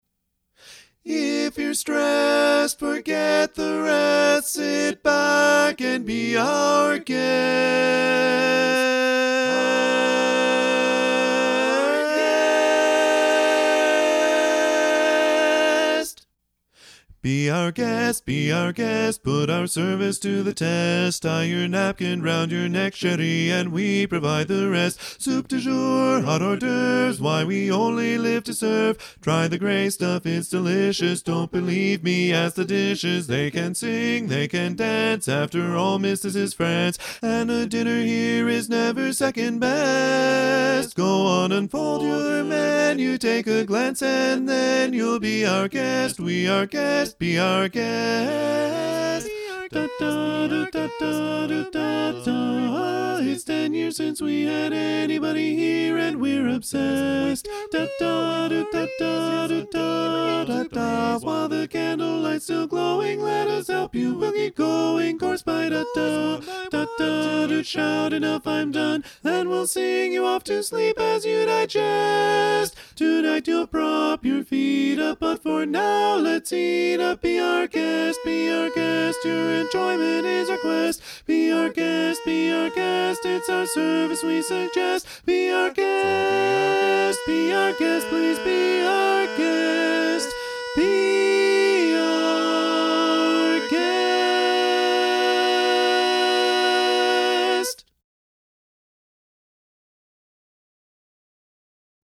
Up-tempo
B♭ Major
Lead